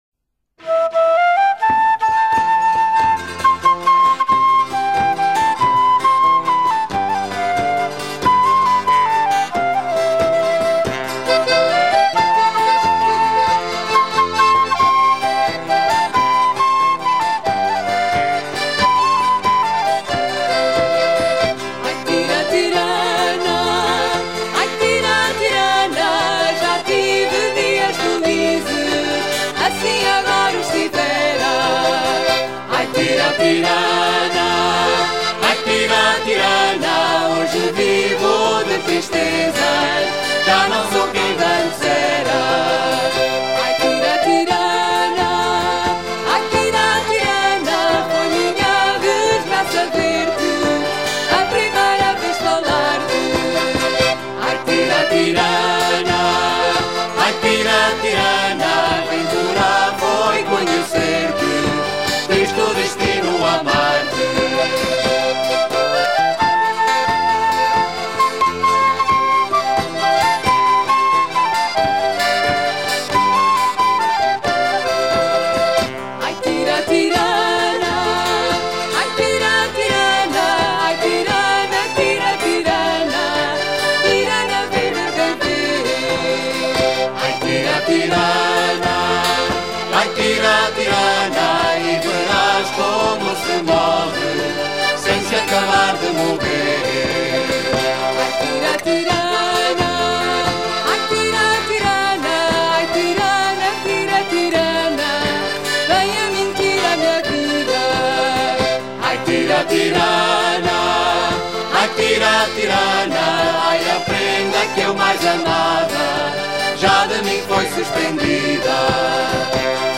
chant gaélique écossais qui décrit une mémorable noce des Morag's
Pièce musicale éditée